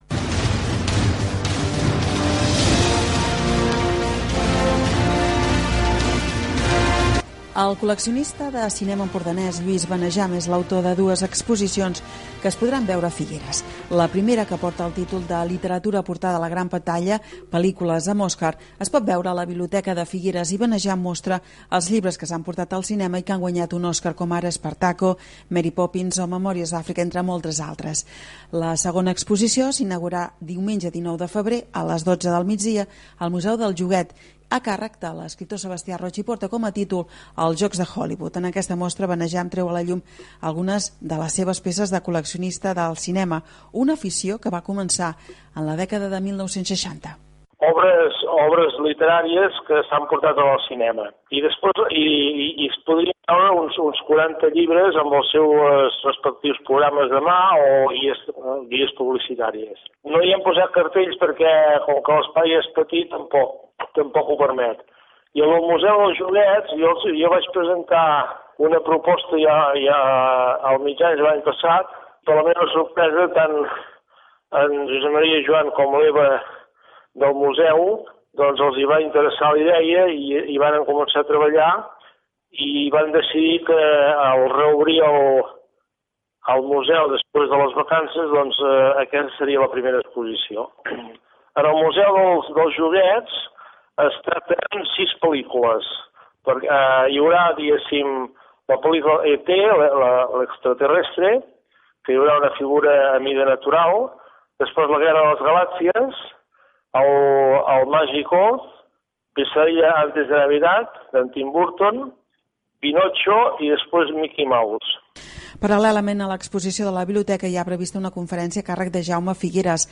exposicions_joguets_i_llibres_radio.mp3